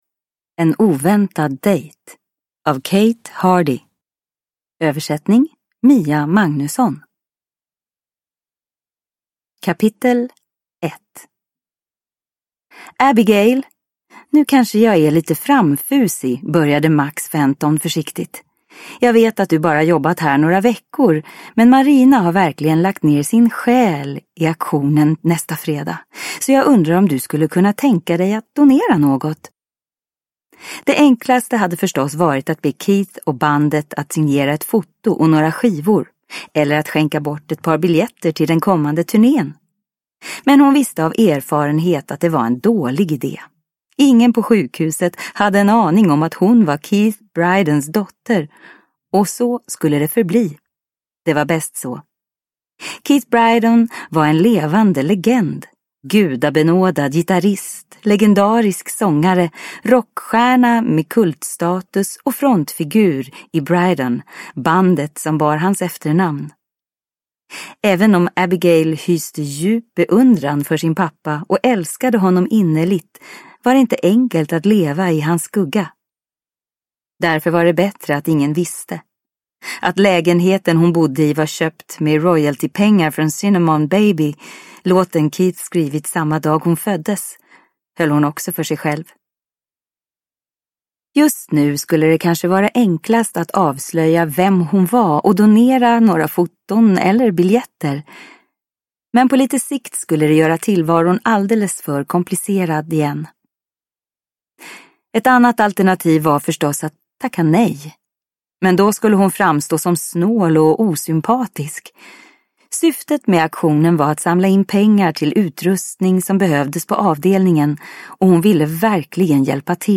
En oväntad dejt – Ljudbok – Laddas ner